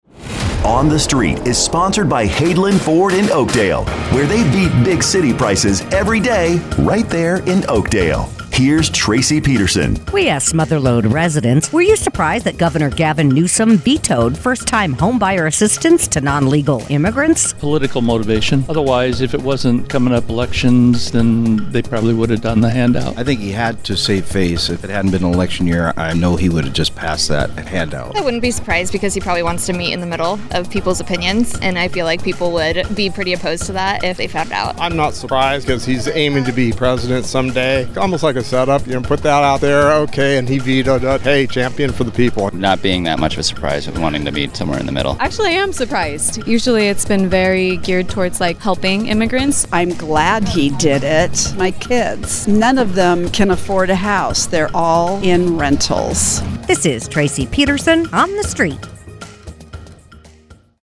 asks Mother Lode residents